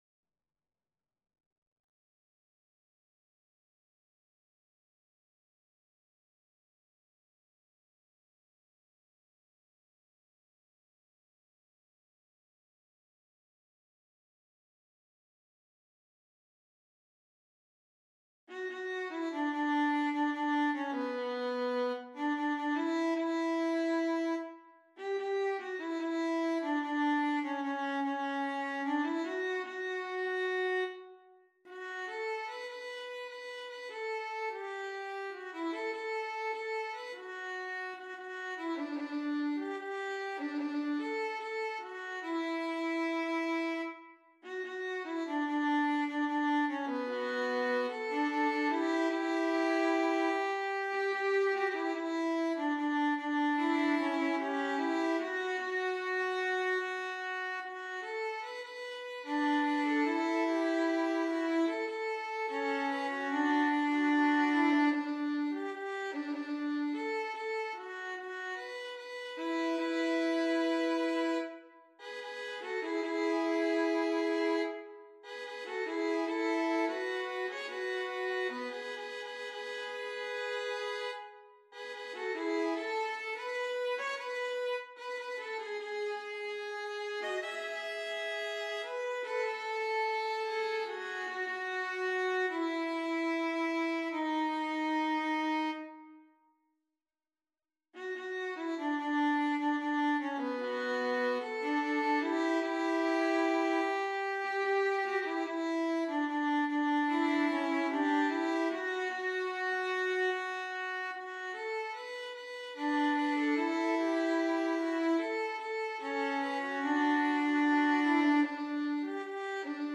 SAB + piano/band